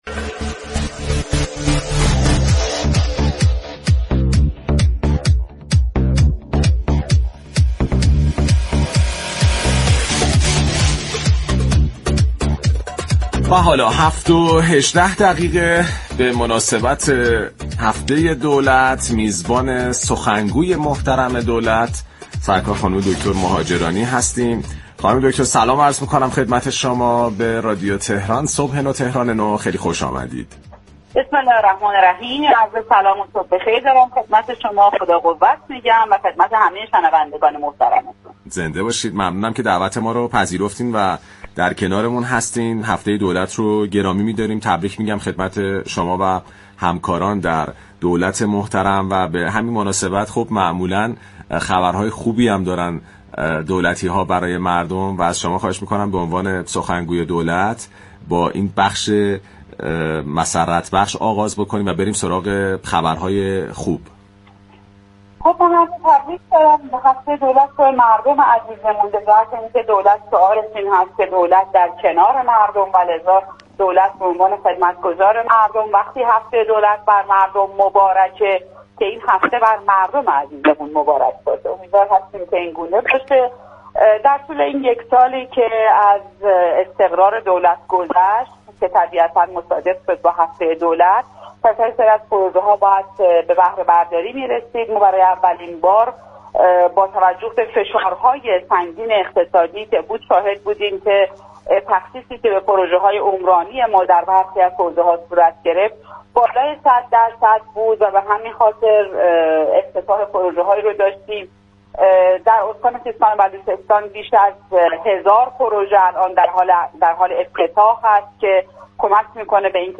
سخنگوی دولت در گفت‌وگو با رادیو تهران به مناسبت هفته دولت؛ از افتتاح 33 هزار پروژه عمرانی،ادامه روند رفع فیلترینگ، برنامه‌های مسكن، اشتغال، حقوق زنان و اصلاح یارانه‌ها خبر داد.